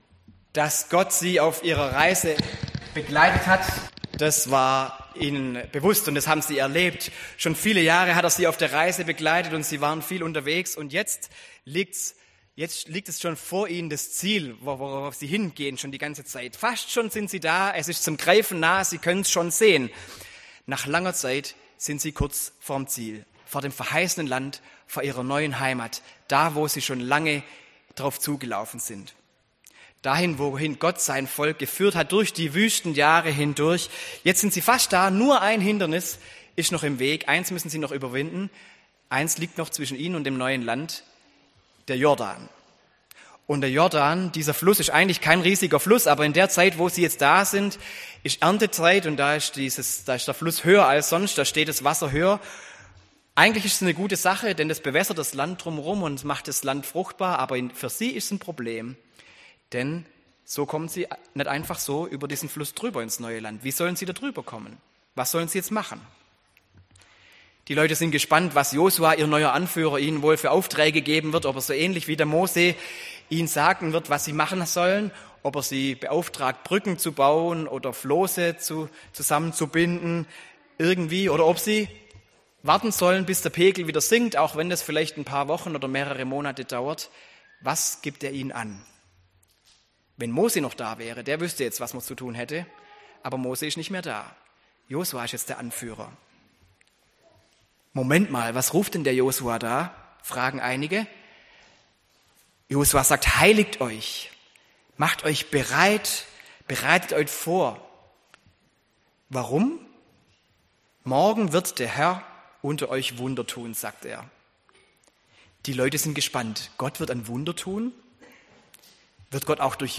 Die Predigten aus den Gottesdiensten unserer Gemeinde finden sie zum Nachlesen und Nachhören in diesem Podcast.